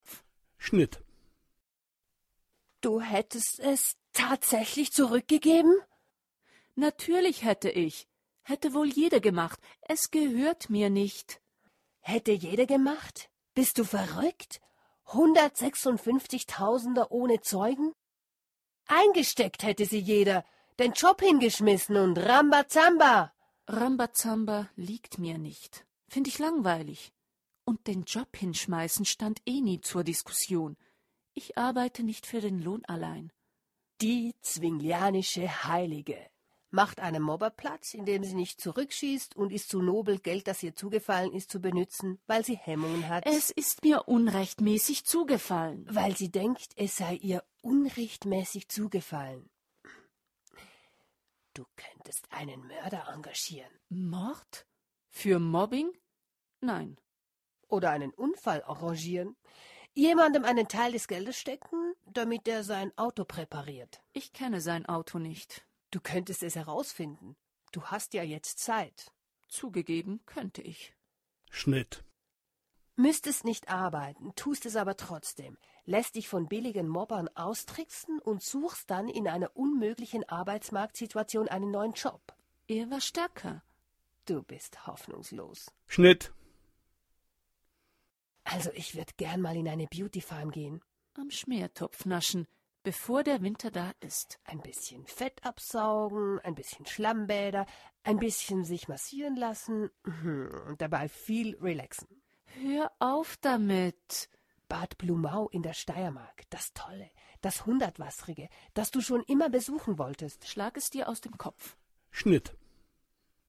Das Hörspiel  -  zufällige Hörproben